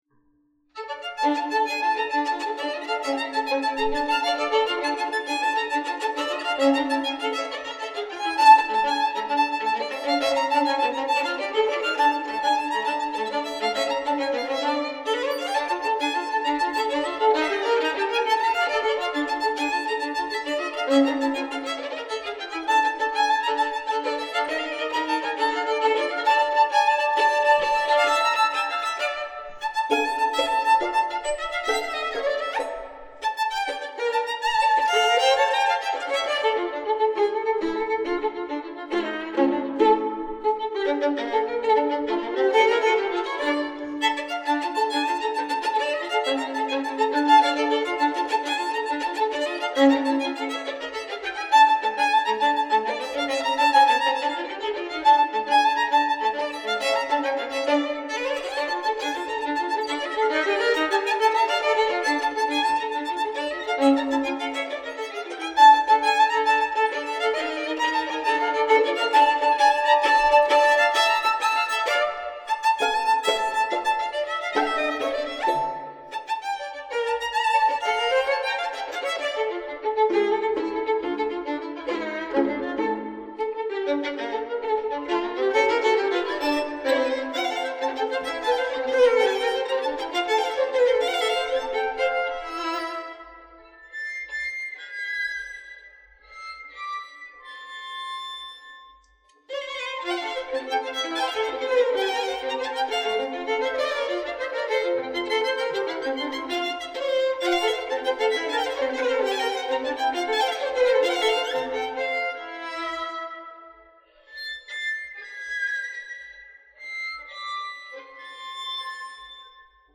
Sizilianische Tarantella „Sette passi“, arrangement für 2 Violinen (eine Version für Violine und Bratsche steht auch zur Verfügung):
1.Violine
2.Violine
tarantella-sette-passi.mp3